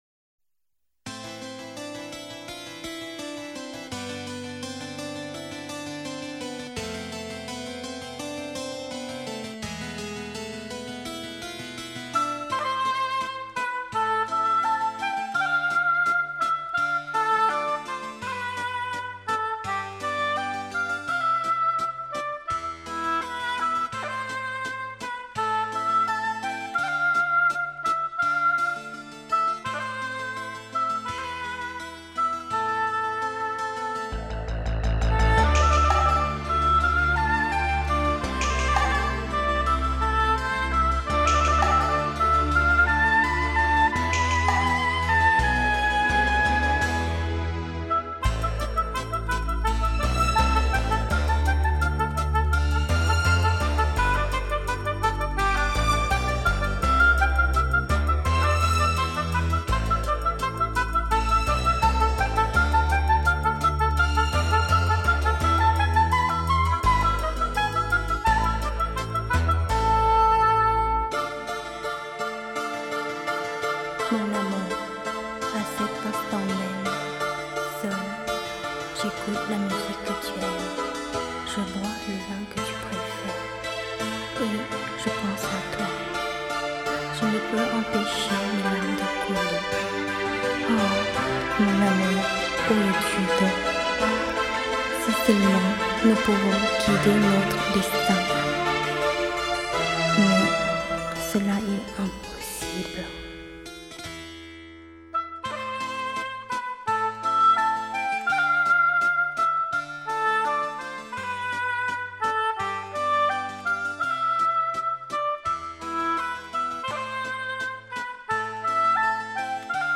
亚洲最优秀的双簧管演奏家为您献上的精选集，他用心爱的双簧管为您诠释出法国的浪漫之情。